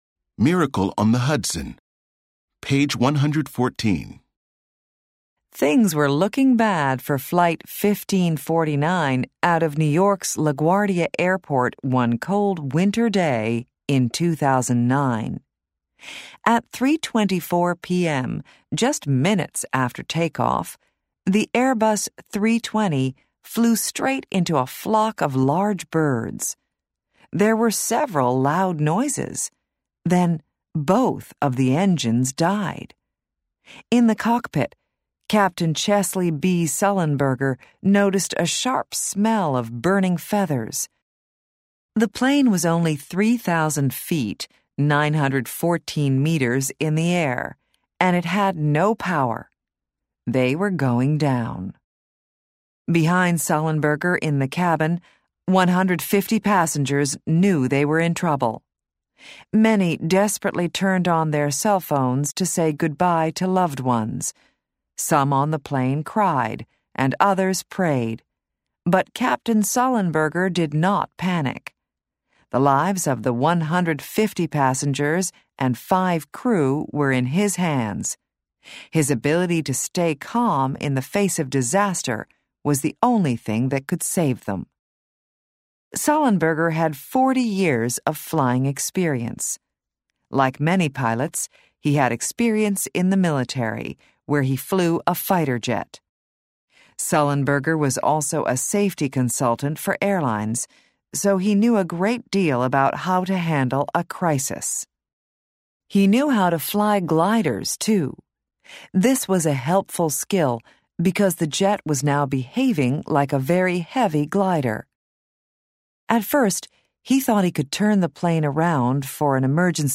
Bound into the back of the book is an audio CD that contains audio recordings of all the stories in the Student's Book.